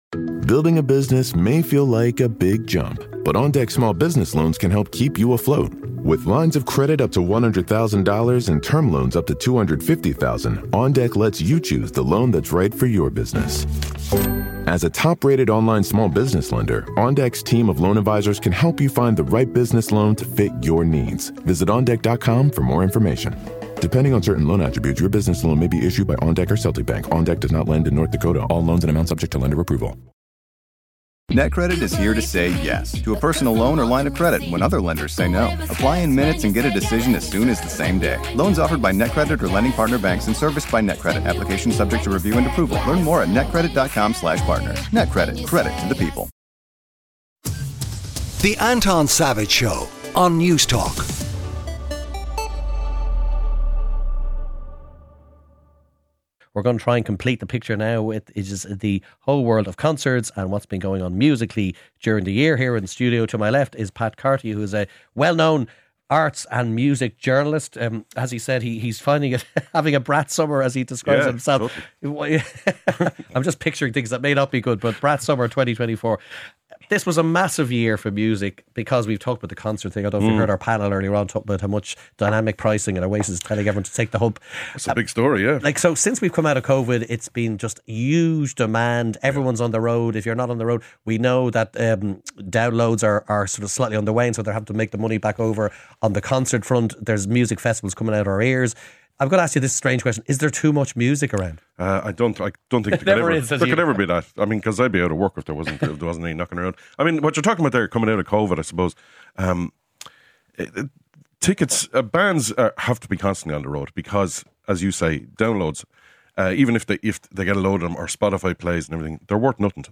Anton Savage presents a bright, lively and engaging current affairs magazine show to kickstart your weekend. The programme features all the latest news, features and opinion along with conversations about the conversations you are having on a Saturday morning from 9-11am.